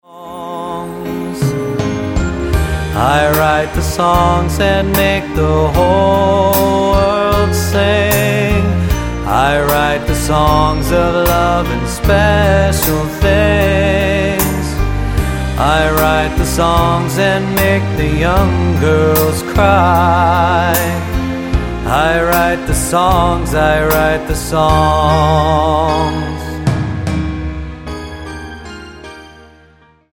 Tonart:Eb-G-A Multifile (kein Sofortdownload.
Die besten Playbacks Instrumentals und Karaoke Versionen .